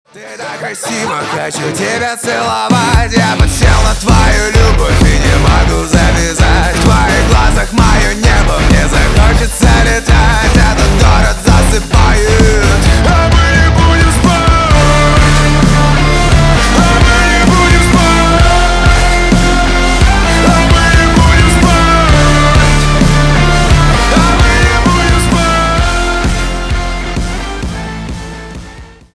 на русском про любовь громкие